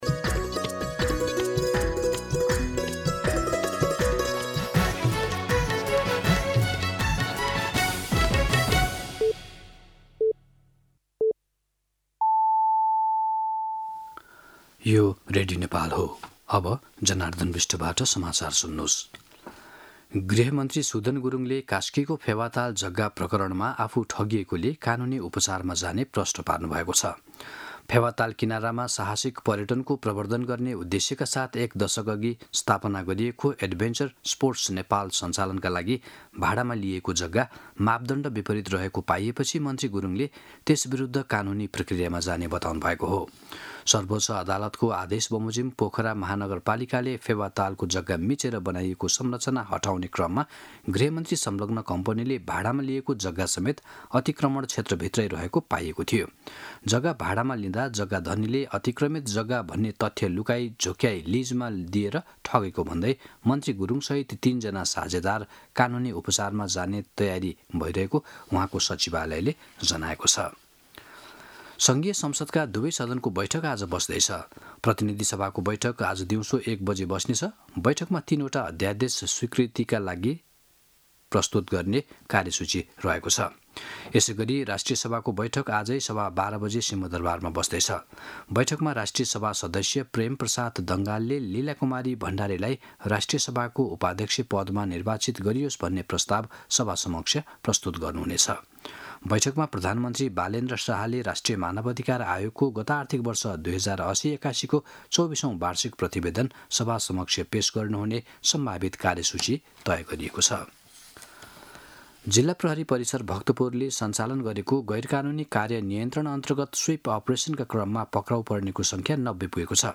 मध्यान्ह १२ बजेको नेपाली समाचार : २३ चैत , २०८२